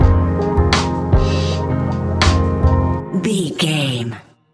Ionian/Major
F♯
laid back
Lounge
chilled electronica
ambient